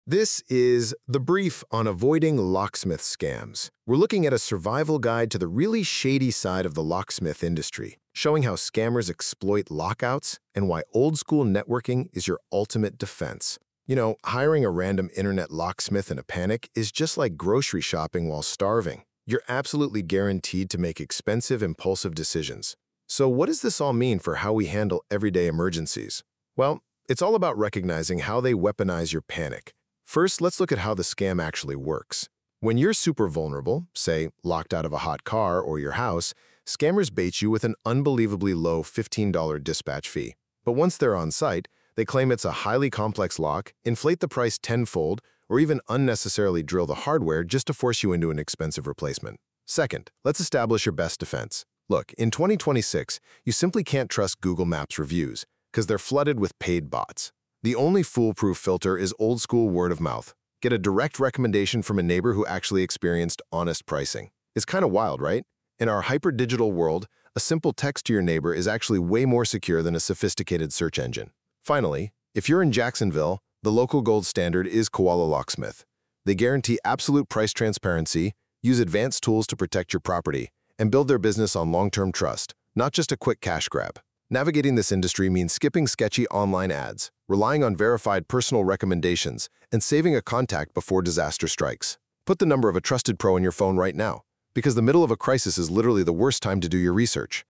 Short narrated guides on car key scams, emergency lockouts, luxury programming, and Florida-specific gotchas.